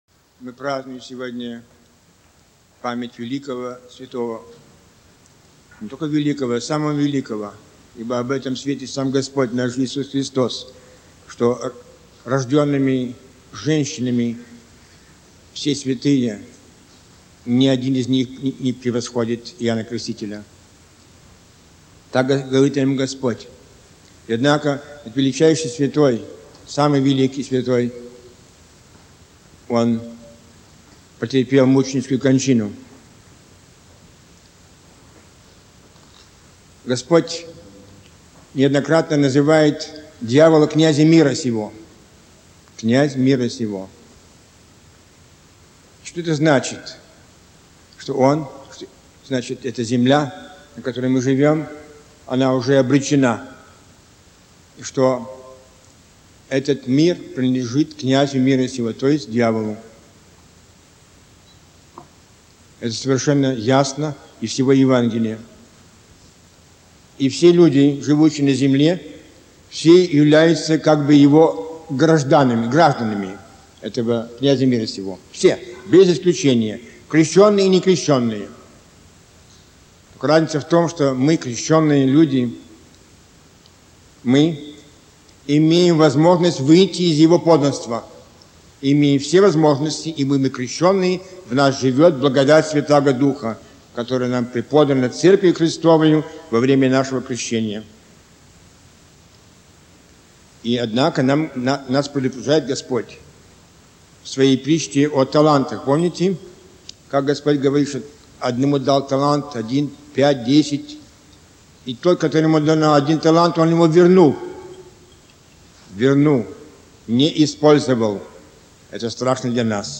Проповеди Блаженнейшего митрополита Виталия